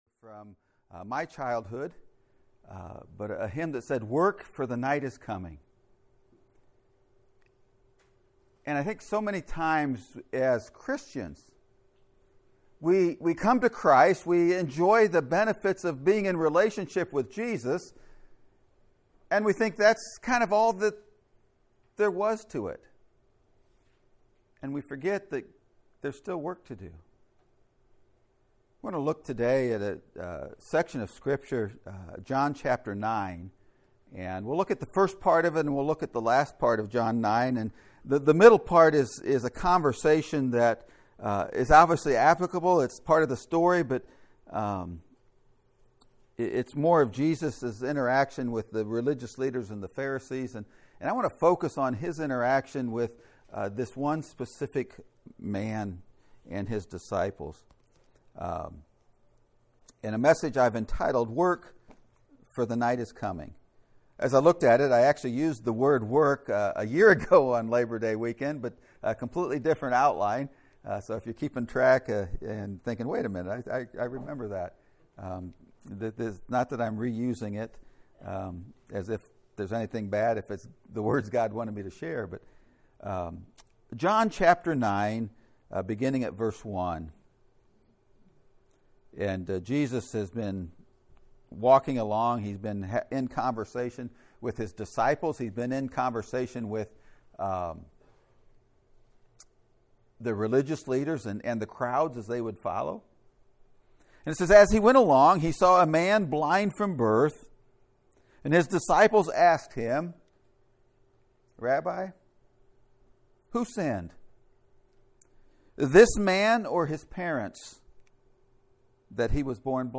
WORK, For the Night is Coming! (Sermon Audio)